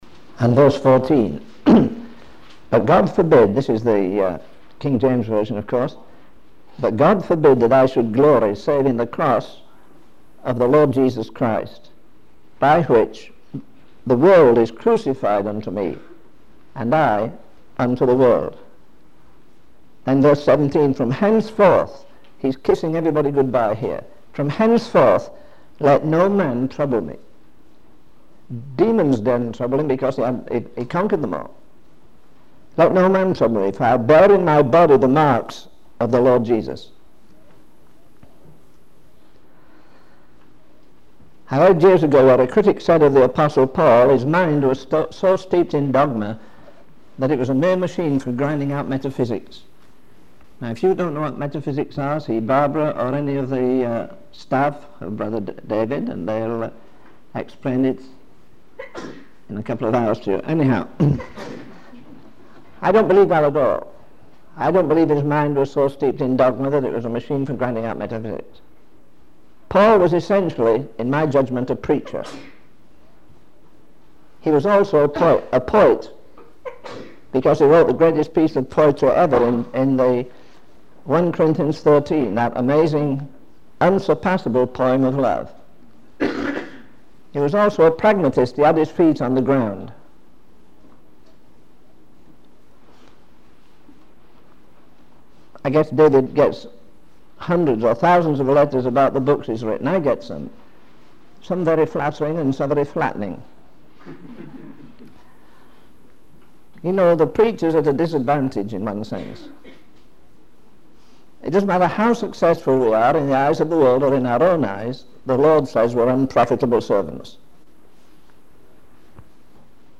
In this sermon, the preacher reflects on the state of humanity and their longing for something more. He observes that people from all walks of life, whether intellectual or materialistic, seem to be content with superficial things.